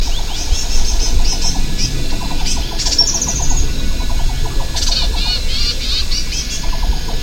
Erpornis à ventre blanc ( Erpornis zantholeuca ) ssp griseiloris
Cris enregistrés le 02 juillet 2011, en Chine, province du Guangdong, réserve de Nan Kun Shan.